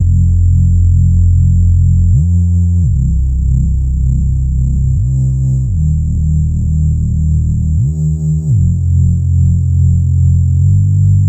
Ambient Bass 85 BPM
Tag: 85 bpm Trap Loops Bass Synth Loops 1.90 MB wav Key : Unknown